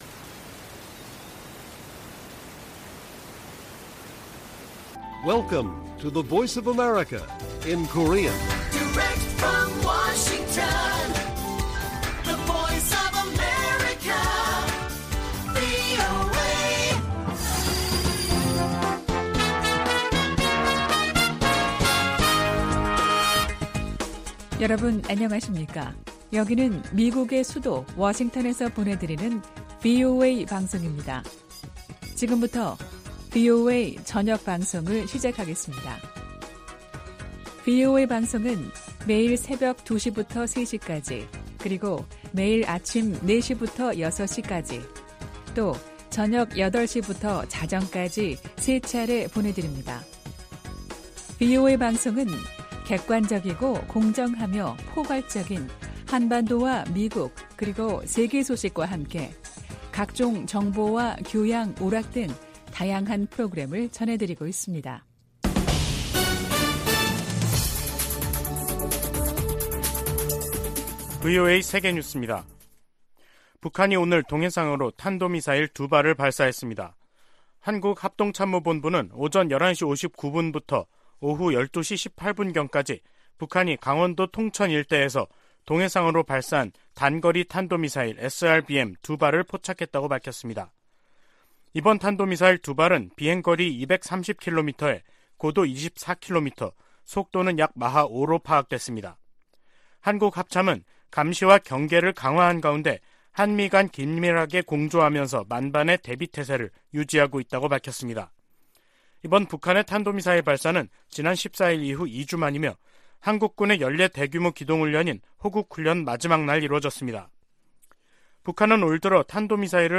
VOA 한국어 간판 뉴스 프로그램 '뉴스 투데이', 2022년 10월 28일 1부 방송입니다. 북한이 28일 동해상으로 단거리 탄도미사일(SRBM) 두 발을 발사했습니다. 북한이 7차 핵실험에 나선다면 국제사회가 엄중한 대응을 할 것이라고 백악관 고위관리가 밝혔습니다. 미 국방부는 북한 정권이 핵무기를 사용하고 살아남을 수 있는 시나리오는 없다고 경고했습니다.